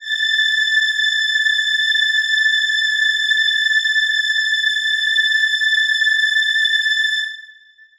Choir Piano (Wav)